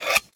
Minecraft Version Minecraft Version 1.21.5 Latest Release | Latest Snapshot 1.21.5 / assets / minecraft / sounds / block / beehive / shear.ogg Compare With Compare With Latest Release | Latest Snapshot
shear.ogg